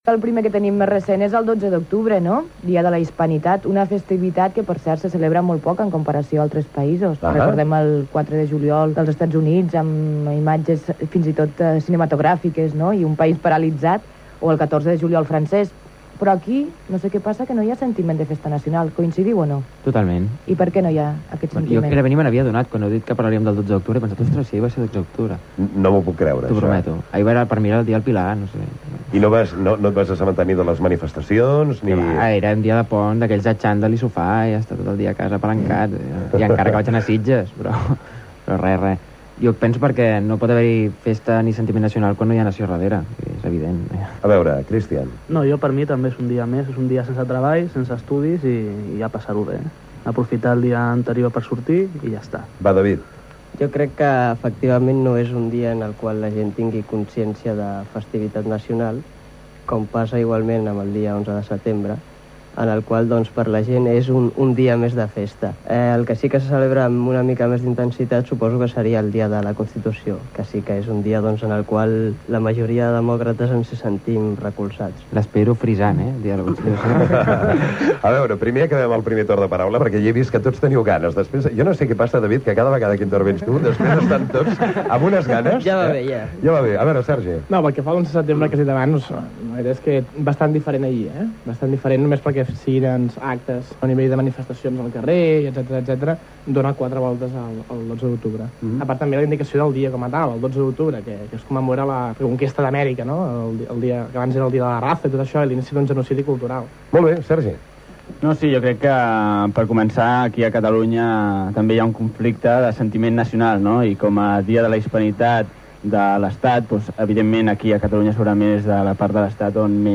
Tertúlia de joves sobre la festa nacional espanyola del 12 d'octubre, Dia de la Hispanidad